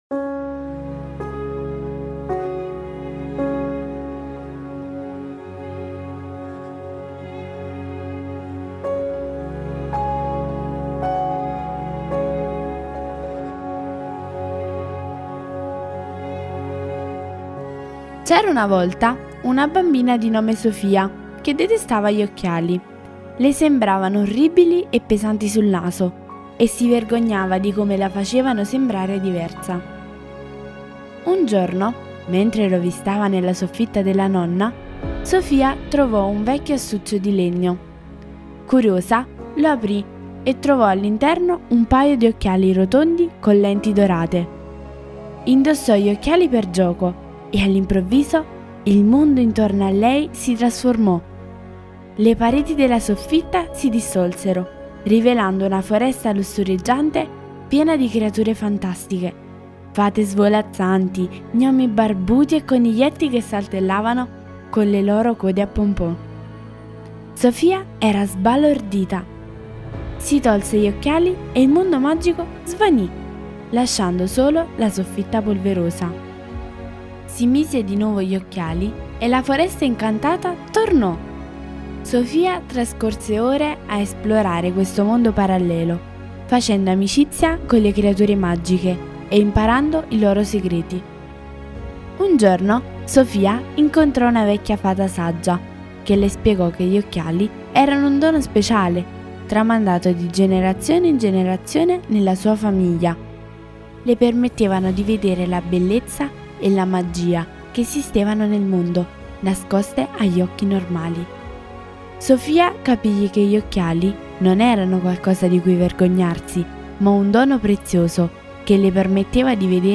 Le favole della buonanotte
Leggiamo insieme la favola de ‘La bambina con gli occhiali magici’